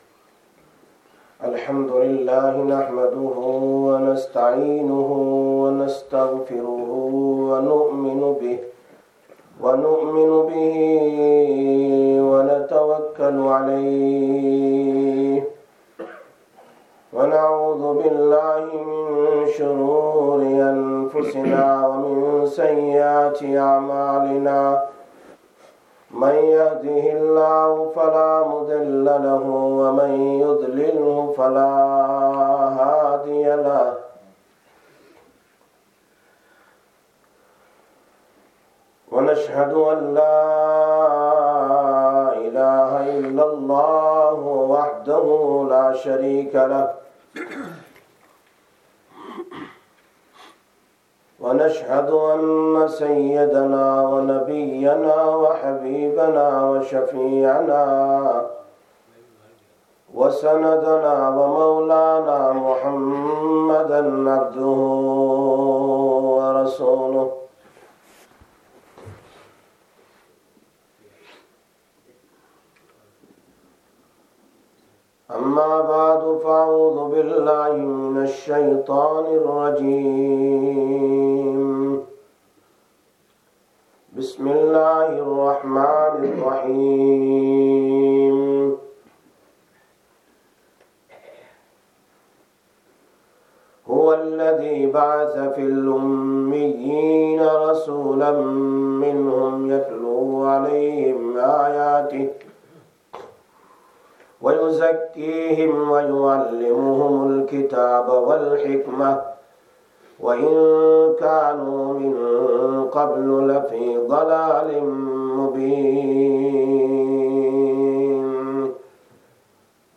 02/12/16 Jumma Bayan, Masjid Quba